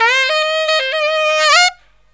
63SAXMD 01-L.wav